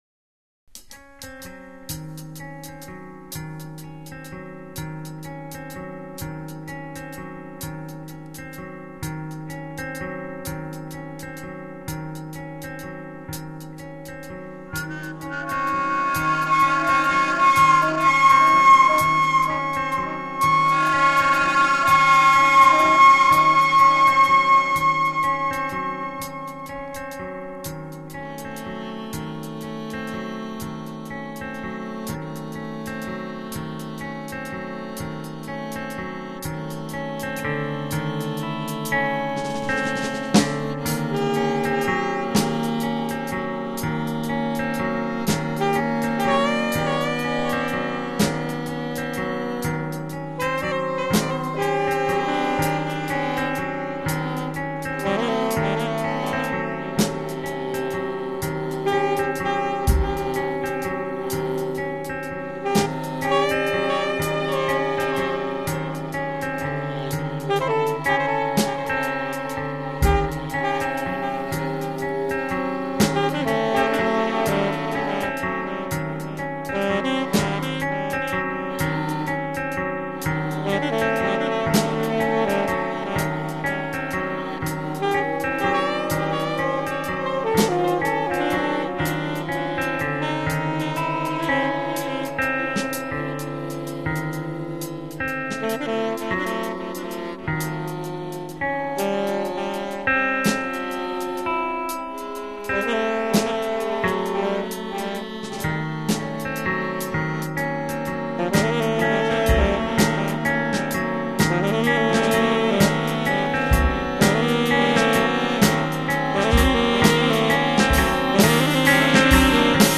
guitar and drums
avantjazz saxophonist